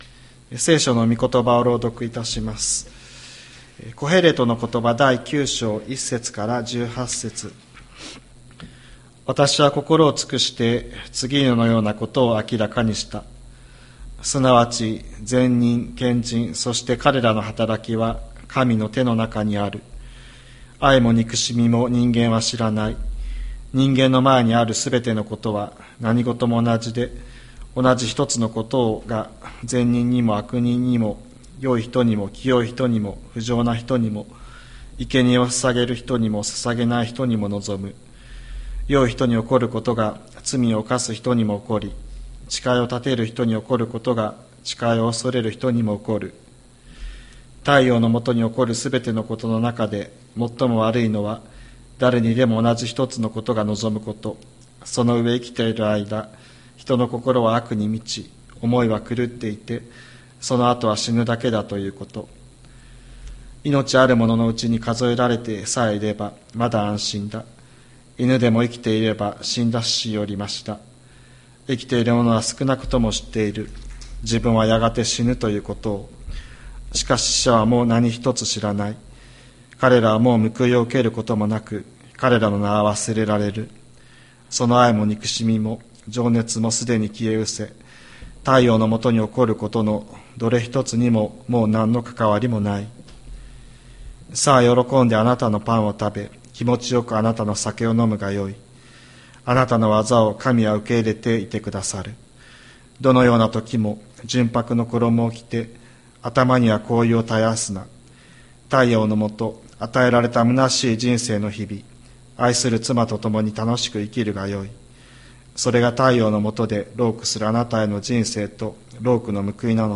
千里山教会 2024年12月29日の礼拝メッセージ。